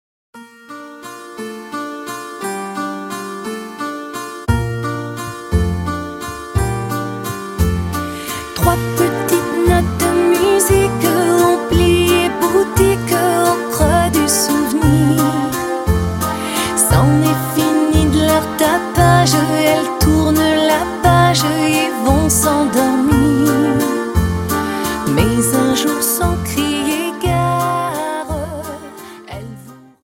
Dance: Viennese Waltz 58